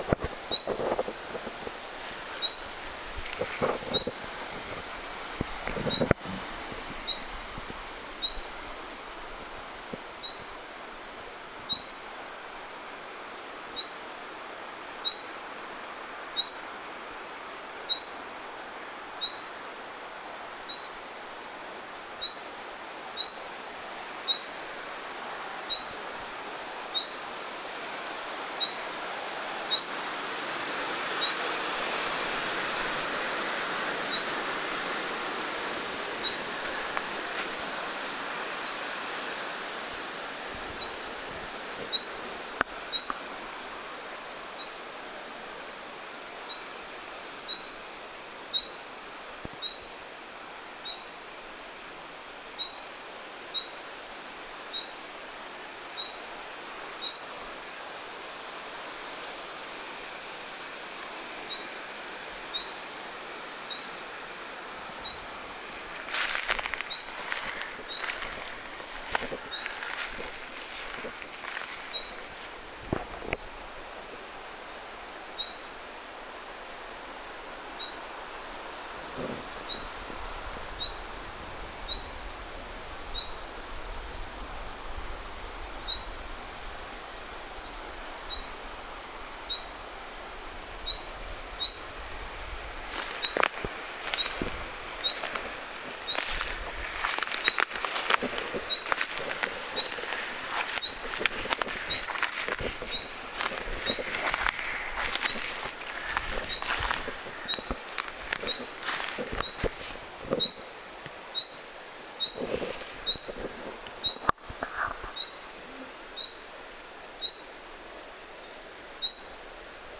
hyit-tiltaltin huomioääntelyä
hyit-tiltaltti_huomioaantelee.mp3